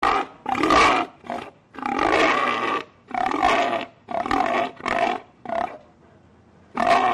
Kategorien Tierstimmen